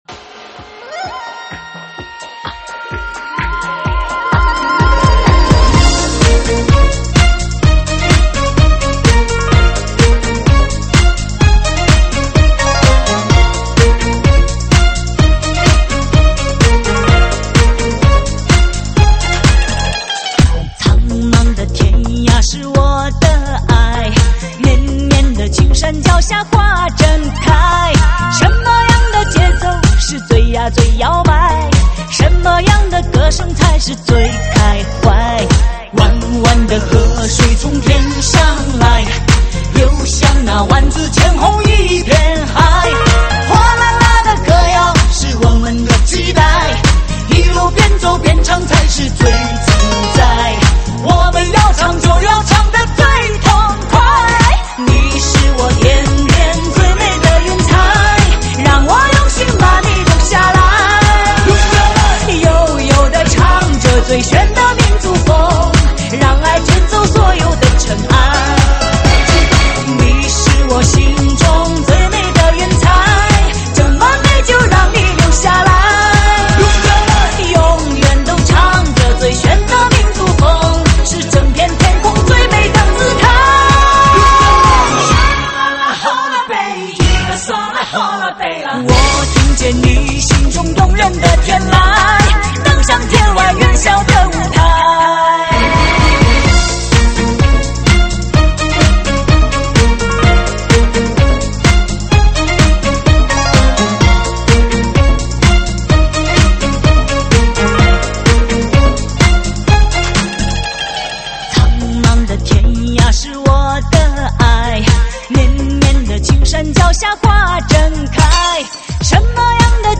3D全景环绕